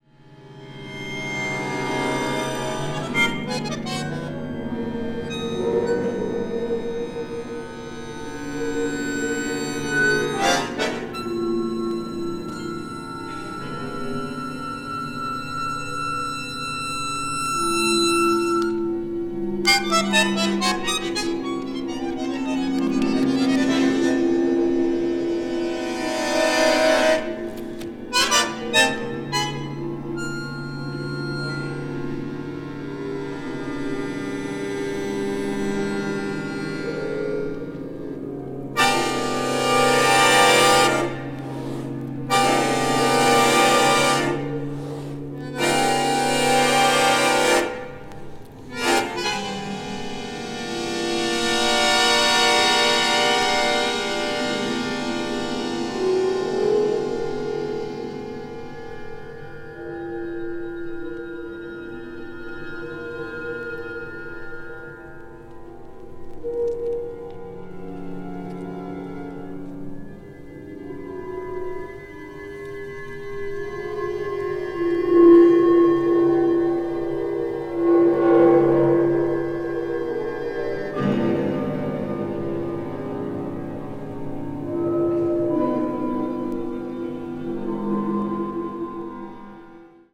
media : EX/EX(some slightly noises.)
The live recording is full of realism.